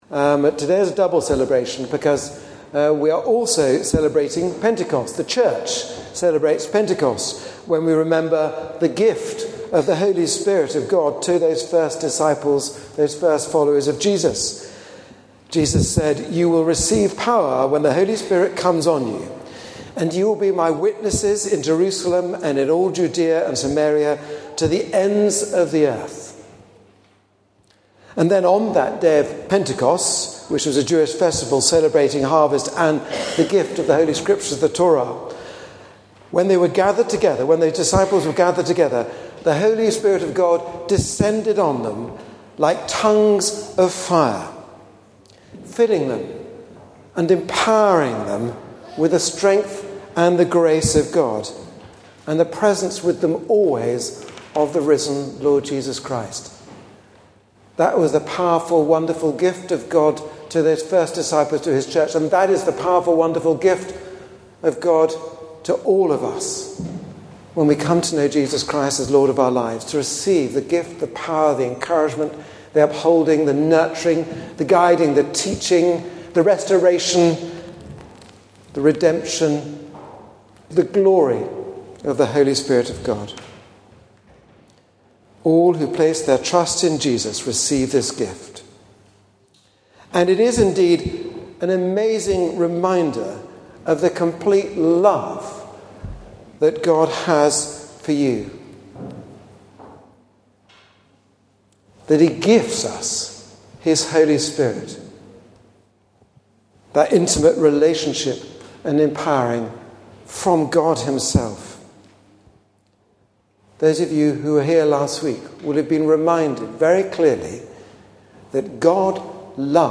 Sermons from the Sunday services and other events as they become available.